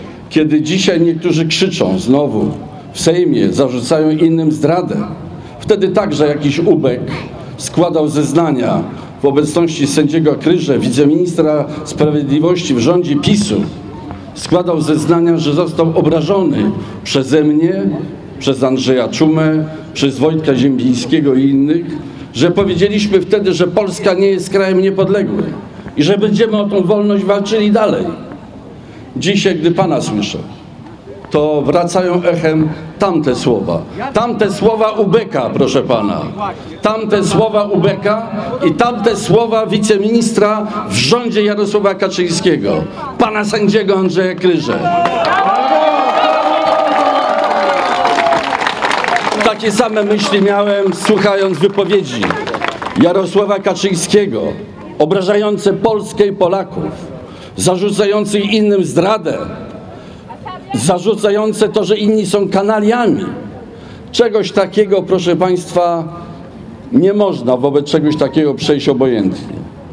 Kiedy wśród zebranych jeden z mężczyzn zarzucił byłemu prezydentowi zdradę, Bronisław Komorowski przypomniał, że w PRLu trafił do więzienia za organizowanie manifestacji niepodległościowej a został skazany przez sędziego Andrzeja Kryże, późniejszego wiceministra sprawiedliwości  w rządzie PiSu.
protest-w-obronie-demokracji-2.mp3